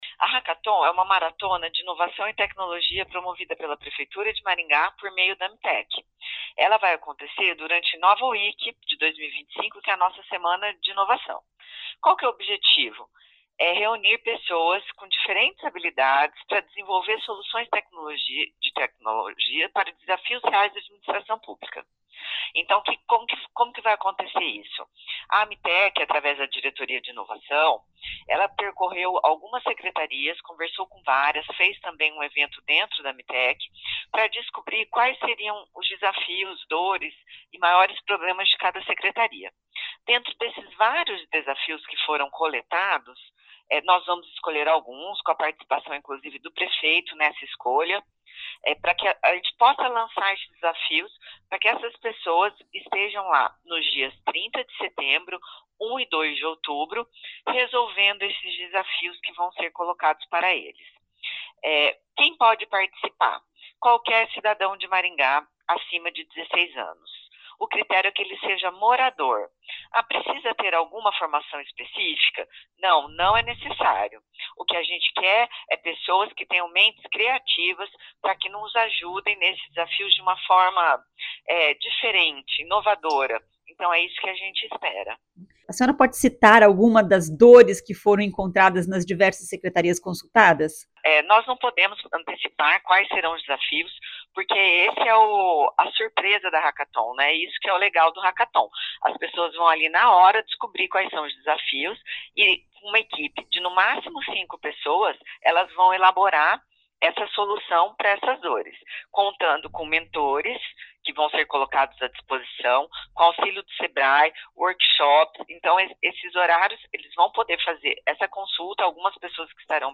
A diretora-presidente da Agência Maringá de Tecnologia e Inovação (Amtech), Cristiane Hasegawa, diz que haverá distribuição de prêmios.
Ouça o que diz a secretária: